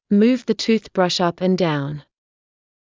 ﾑｰﾌﾞ ｻﾞ ﾄｩｰｽﾌﾞﾗｯｼ ｱｯﾌﾟ ｴﾝ ﾀﾞｳﾝ